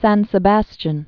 (săn sə-băschĭn, sän sĕ-väs-tyän)